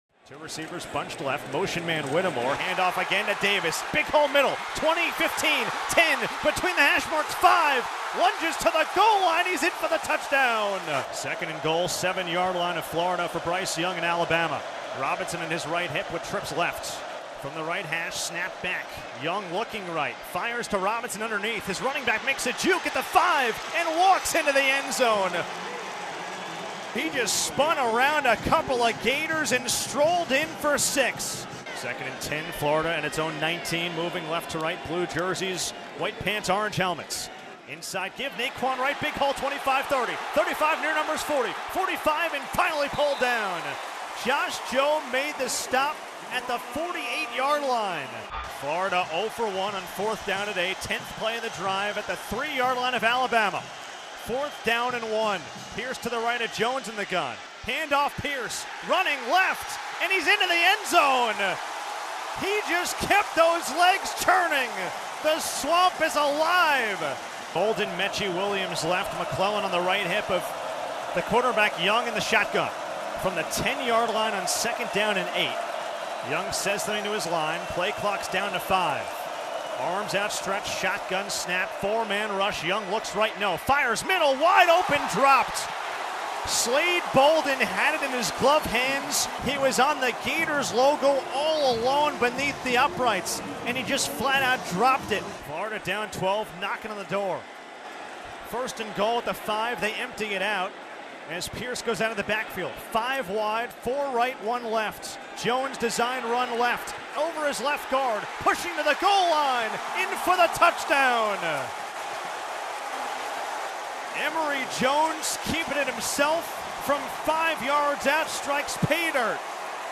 Division I College Football & Basketball Radio Play-by-Play Highlights